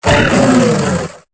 Cri de Diamat dans Pokémon Épée et Bouclier.